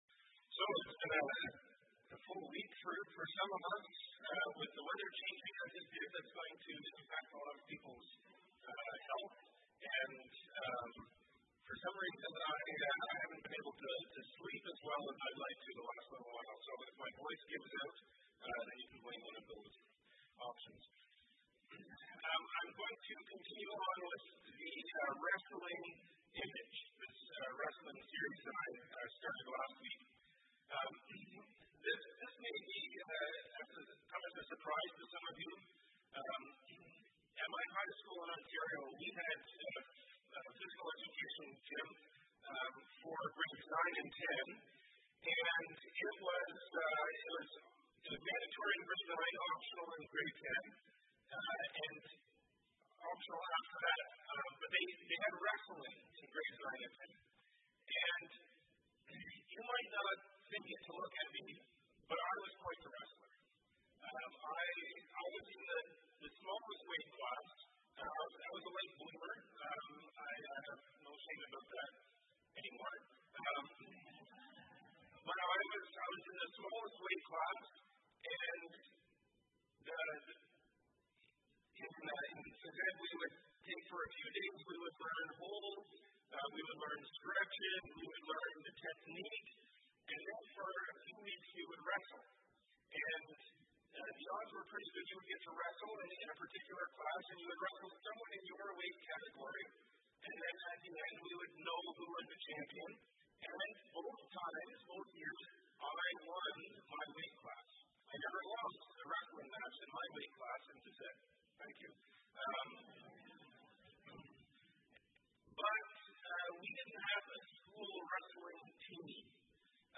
Wrestling Sermon Series – Week 2